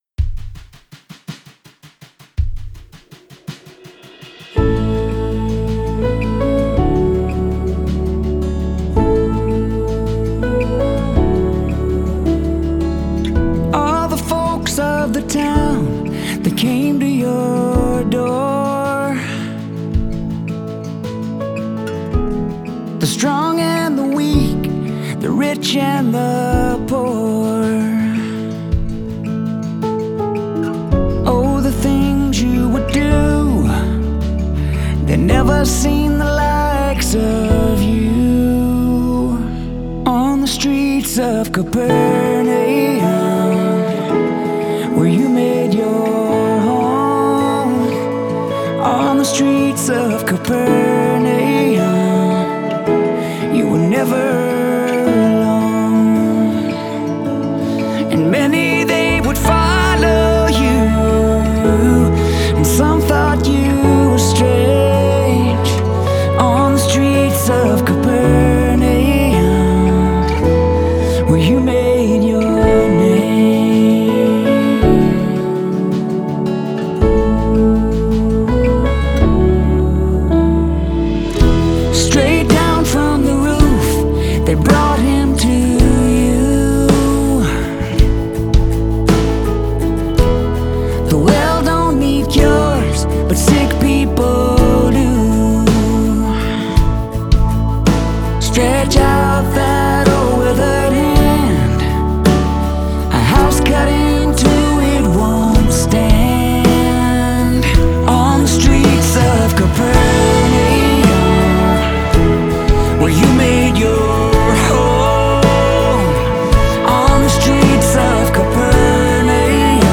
pop soulful style